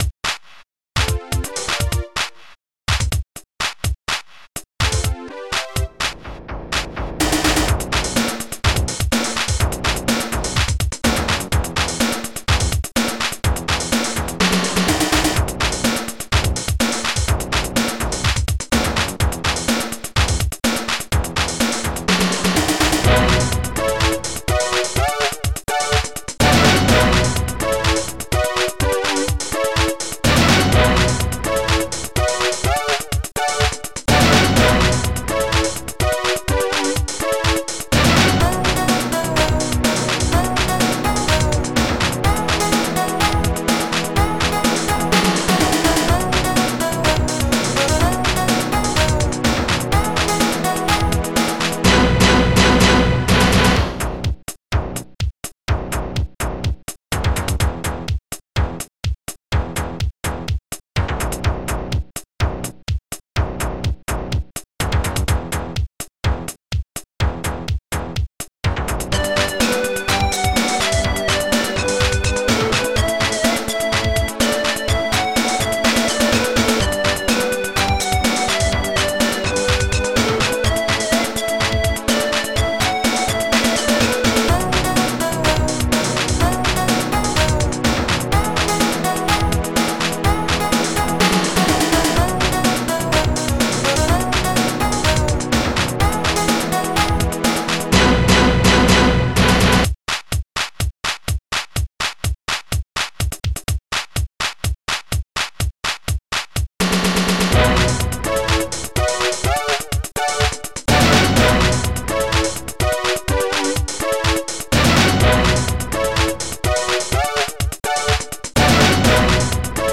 Protracker and family
st-01:m1-handclap
st-15:orch.hit
st-01:m1-snare
st-05:soft string